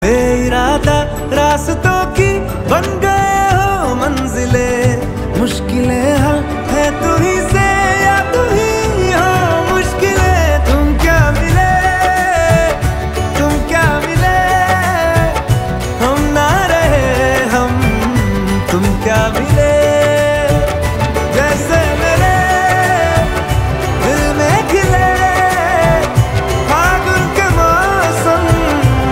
A Melodious Fusion
• High-quality audio
• Crisp and clear sound